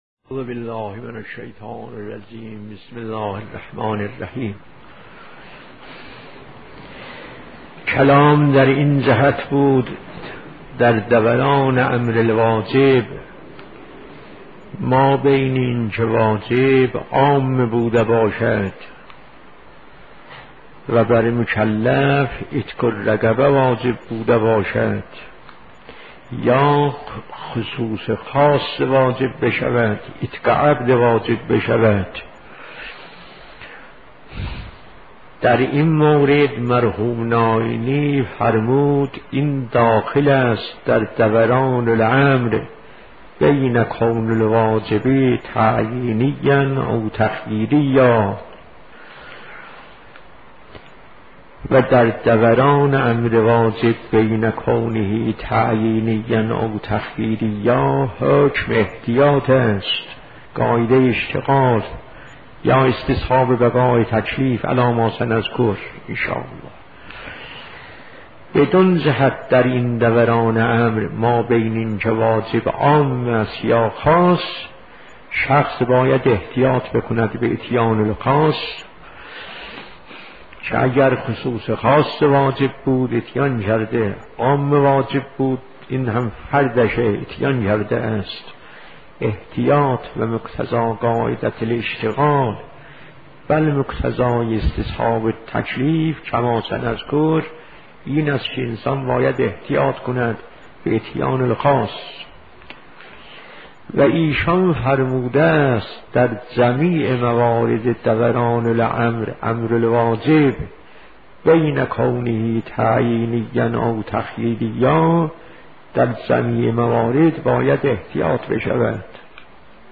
آرشیو سال 77-76 درس خارج اصول مرحوم آیت‌الله میرزا جواد تبریزی